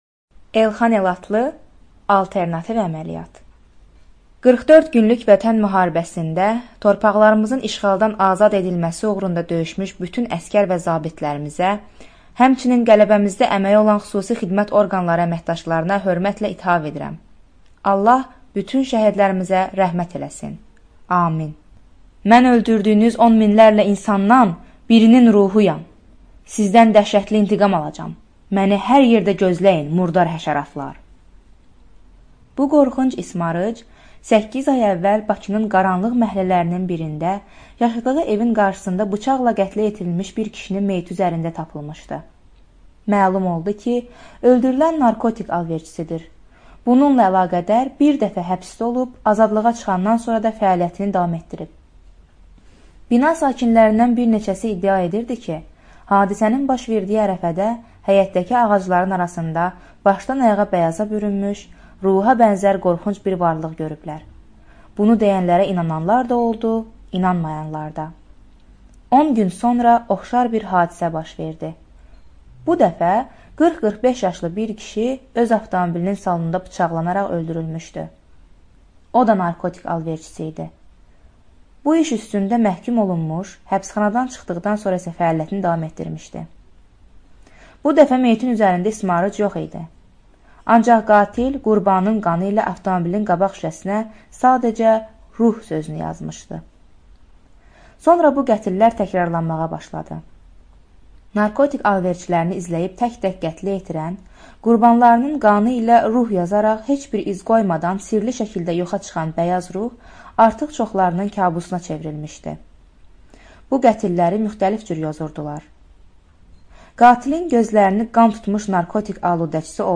Студия звукозаписиСтудия Азербайджанского общества слепых